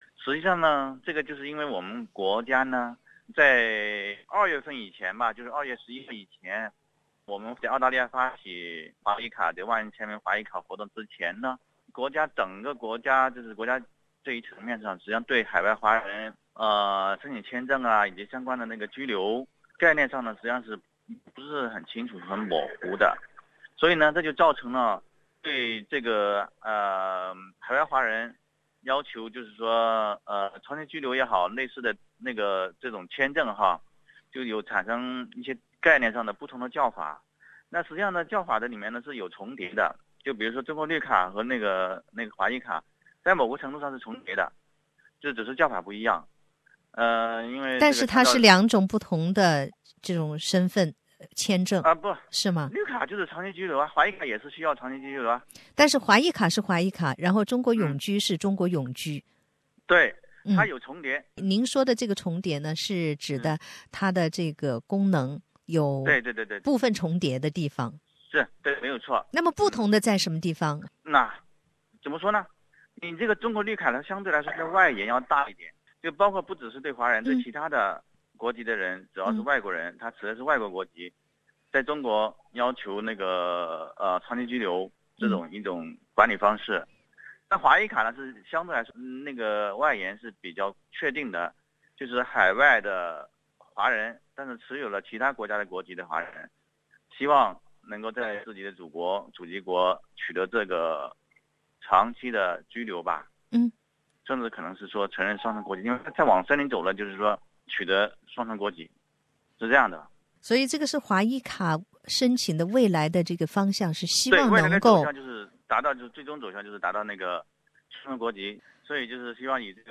请听采访录音 READ MORE 中国政府是否会出台“华裔卡”政策？